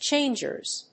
/ˈtʃendʒɝz(米国英語), ˈtʃeɪndʒɜ:z(英国英語)/